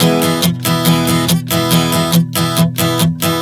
Strum 140 E 04.wav